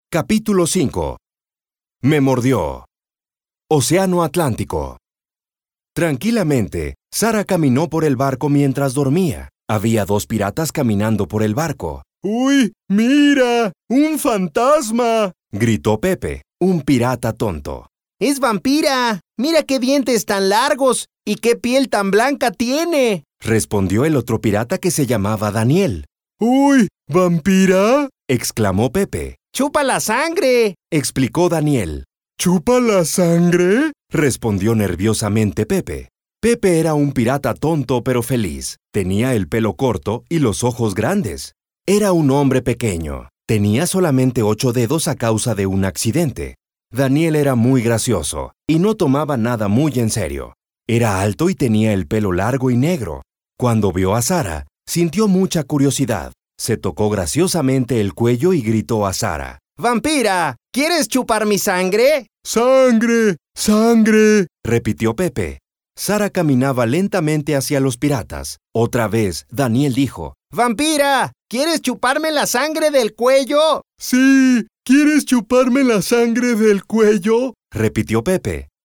Audiobooks
Styles: Warm, Elegant, Natural, Conversational, Sales Man, Corporate, Legal, Deep.
Equipment: Neumann TLM 103, Focusrite Scarlett, Aphex Channel, Source Connect
BaritoneBassDeepLowVery Low